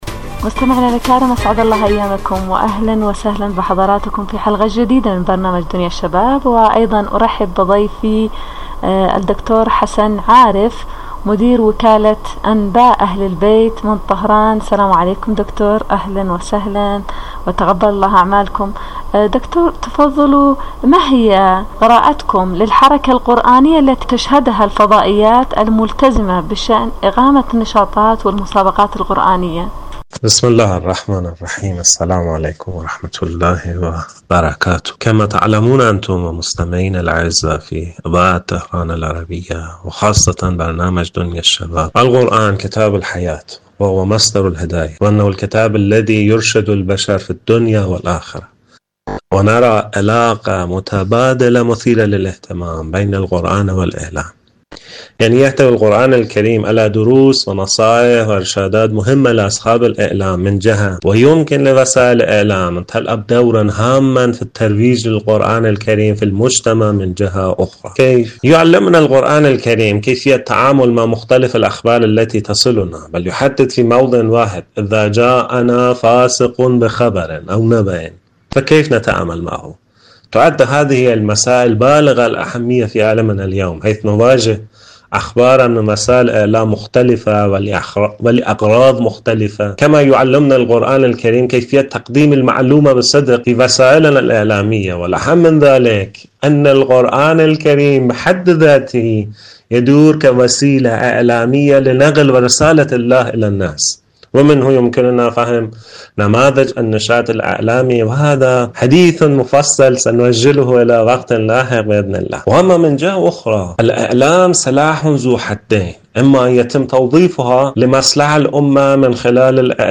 إذاعة طهران- دنيا الشباب: مقابلة إذاعية